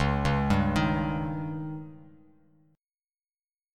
C#sus2b5 Chord